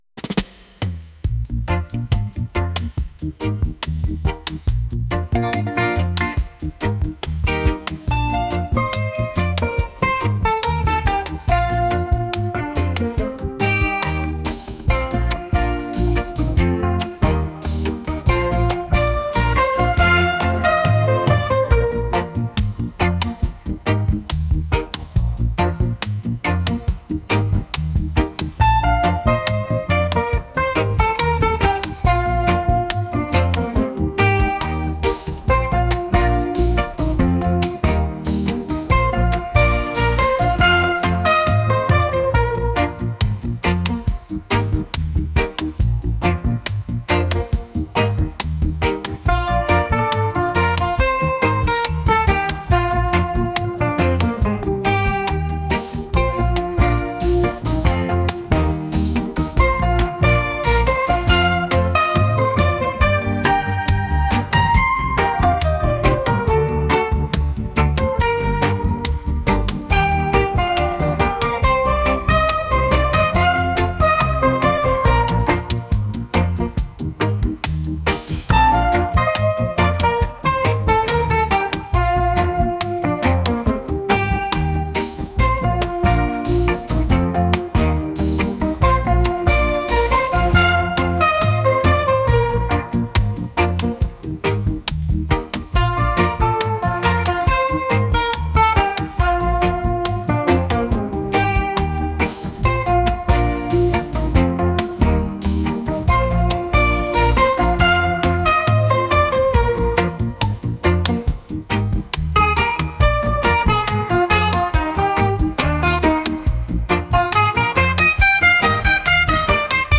reggae | lofi & chill beats